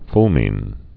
(flmĭn, fŭl-)